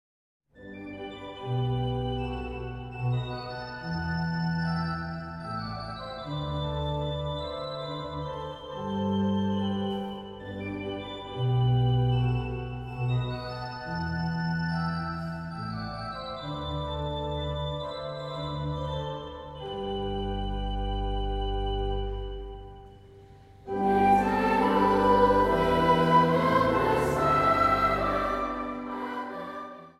piano
dwarsfluit
panfluit
marimba
orgel.
Zang | Kinderkoor